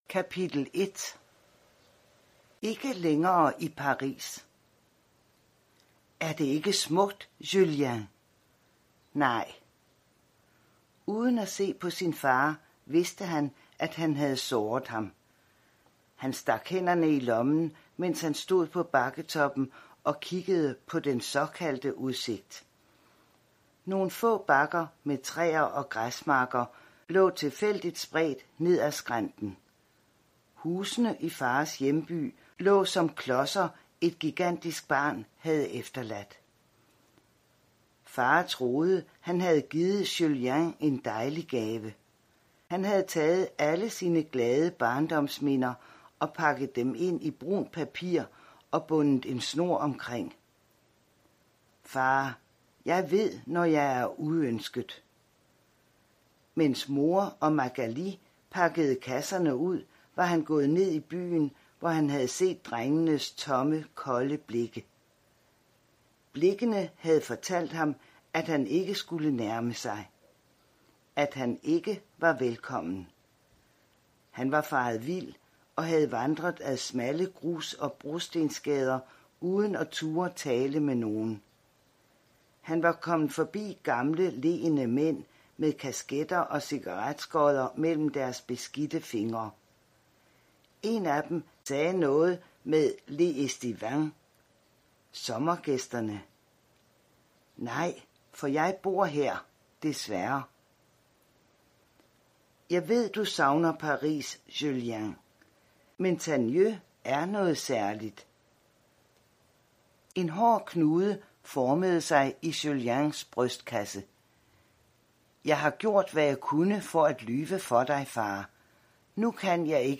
Hør et uddrag af Stor er verden Stor er verden sort er natten Format MP3 Forfatter Heather og Lydia Munn Bog Lydbog E-bog 99,95 kr.